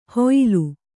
♪ hūyalu